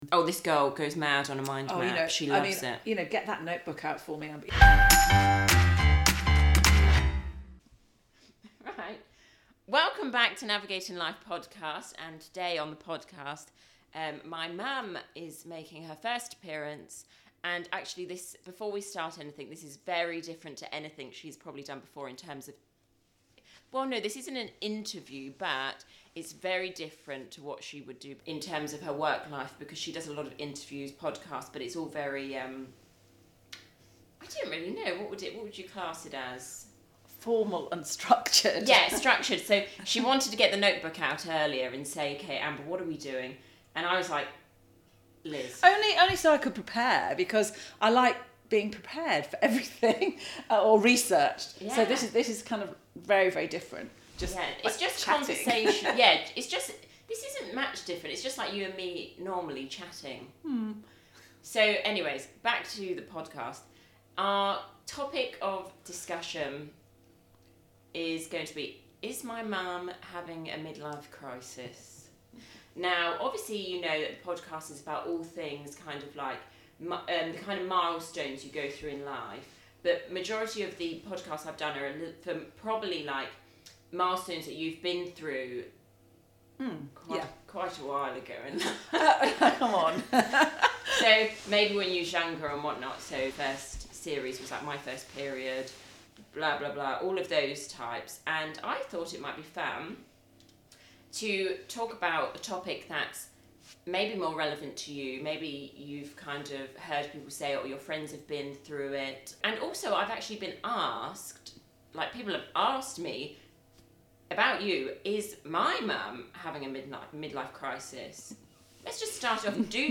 Take a moment to listen to me and my mum chatting about that dreaded mid life crisis!